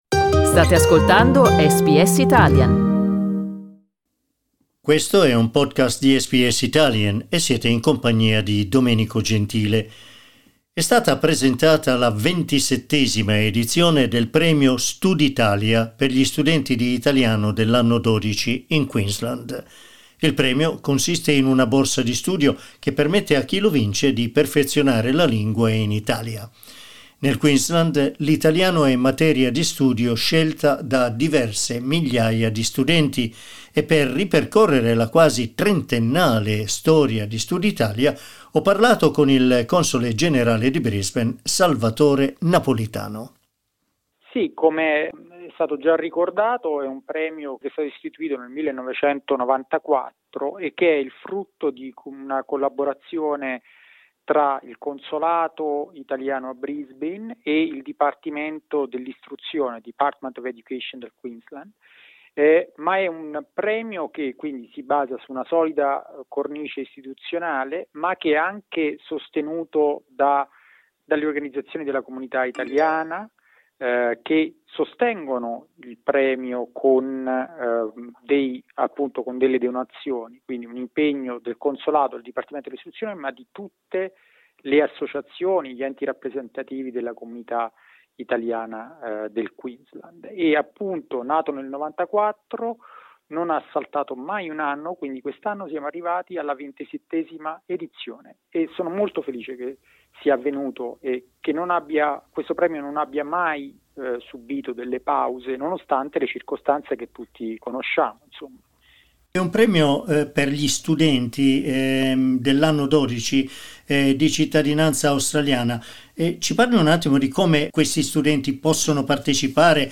Il console generale di Brisbane, Salvatore Napolitano, ci parla dell'edizione del 2021 e conferma che, a causa della chiusura dei confini per la pandemia di COVID-19, i vincitori potranno beneficiare del premio entro il 2025. Ascolta l'intervista al Console Napolitano: LISTEN TO Presentata la 27esima edizione del premio StuditaliA SBS Italian 11:46 Italian Le persone in Australia devono stare ad almeno 1,5 metri di distanza dagli altri.